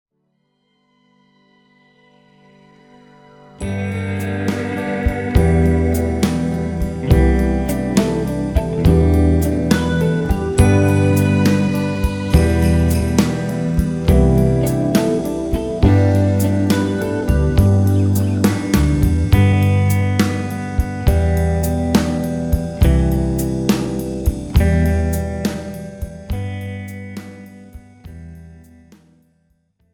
This is an instrumental backing track cover.
• Key – G
• Without Backing Vocals
• No Fade